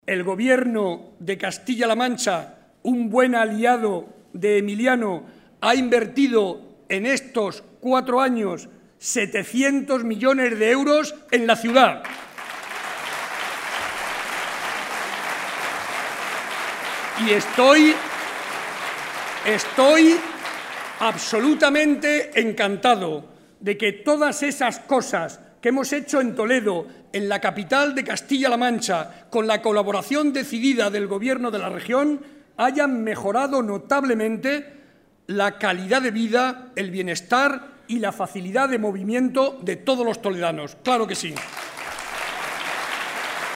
Audio Barreda mitin Toledo 1